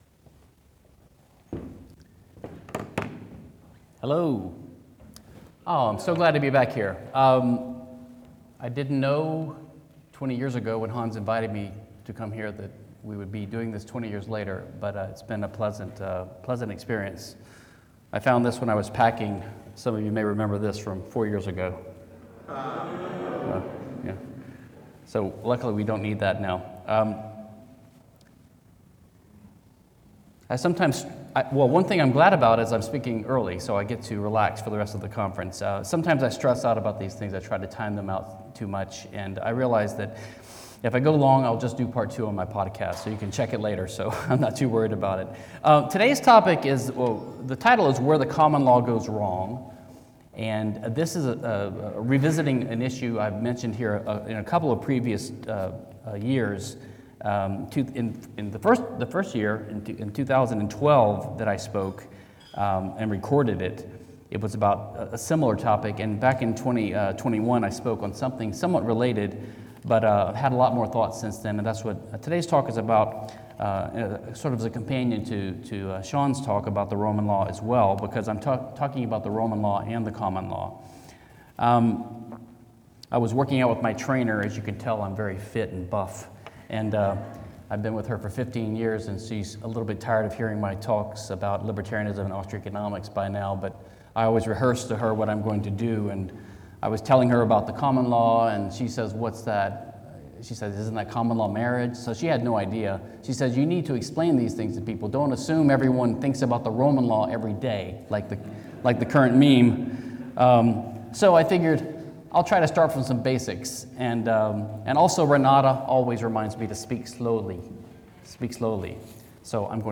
Property and Freedom Podcast, Episode 321. AI-assisted audio narration of the main chapters of Rothbard at 100: A Tribute and Assessment (Papinian Press and The Saif House, 2026) is available at this PFS Youtube Playlist; the mp3 files may also be downloaded in this zip file.